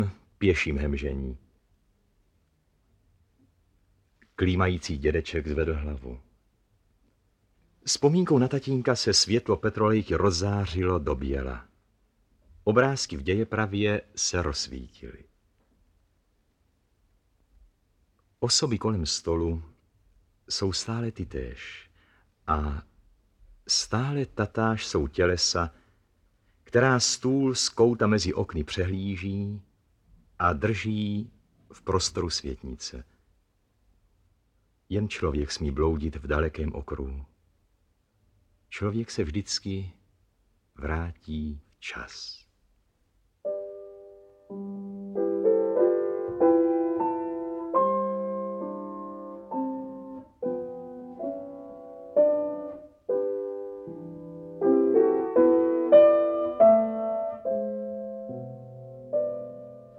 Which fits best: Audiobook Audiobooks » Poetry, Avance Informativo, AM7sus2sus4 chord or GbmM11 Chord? Audiobook Audiobooks » Poetry